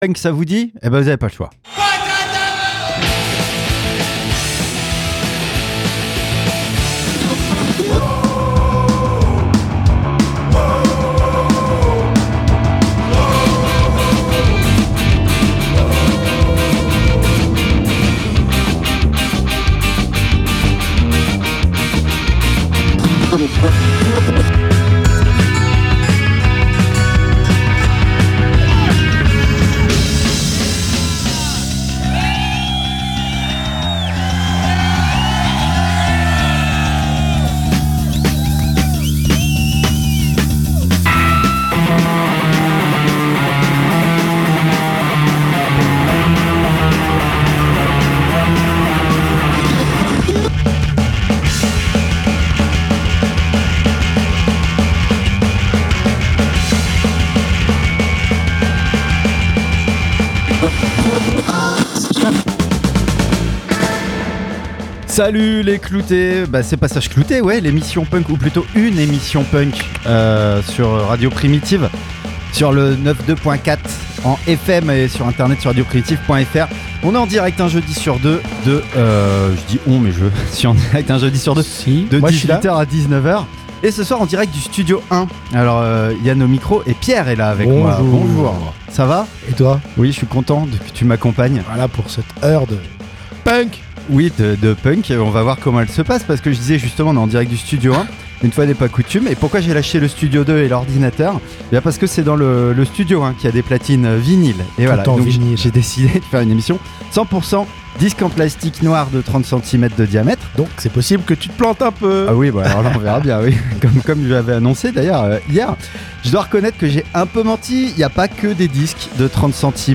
🎧 Spéciale 100% vinyle - Passage clouté